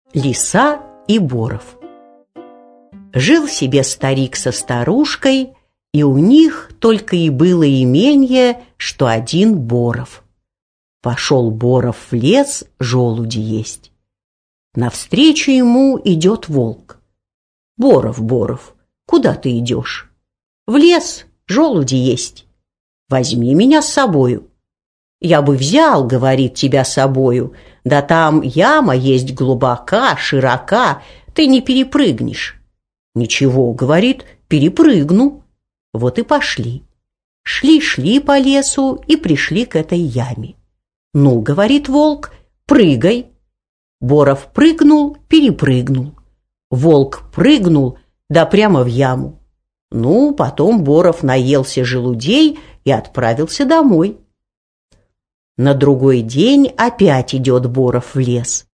Аудиокнига Лиса и Боров | Библиотека аудиокниг